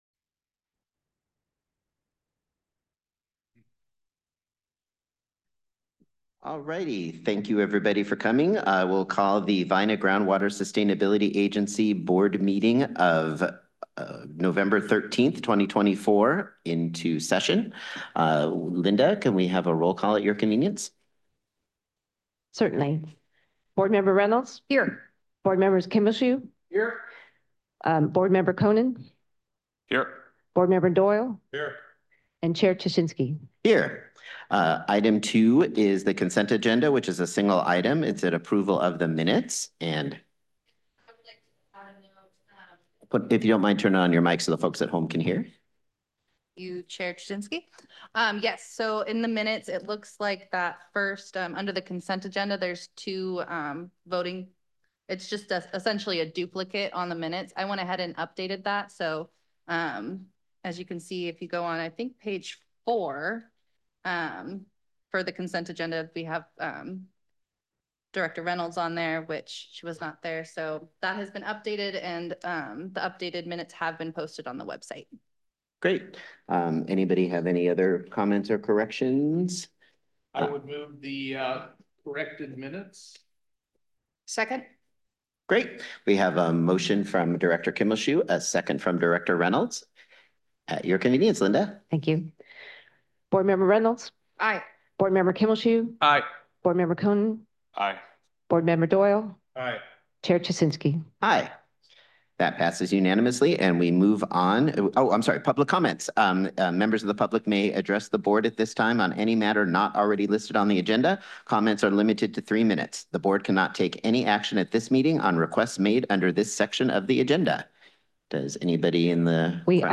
The Vina GSA Board Meetings will are held in-person beginning at 3:30 p.m. on the second Wednesday of the month, or as otherwise scheduled by the…
Board Meeting